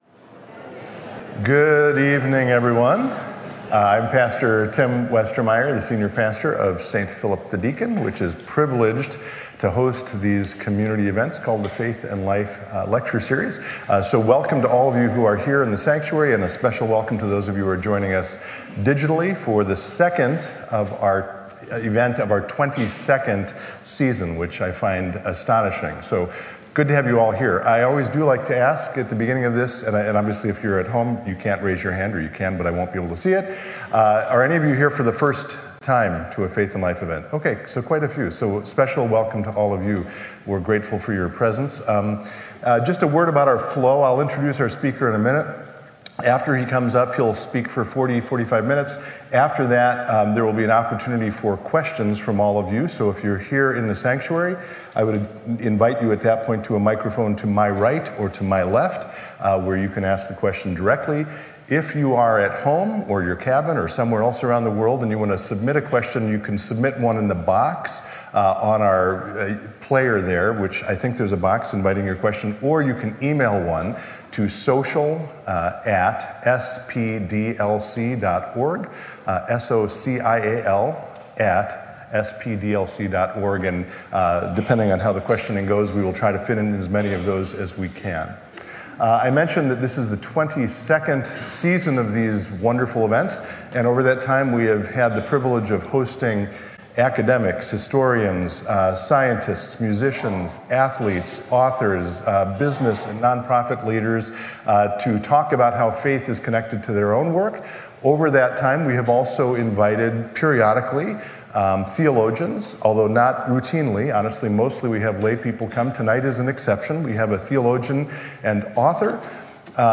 The Faith & Life Lectures are open and welcoming public forums where nationally known speakers reflect on how Christian faith intersects with different dimensions of everyday life.
The series is a community service of St. Philip the Deacon Lutheran Church in Plymouth, Minnesota.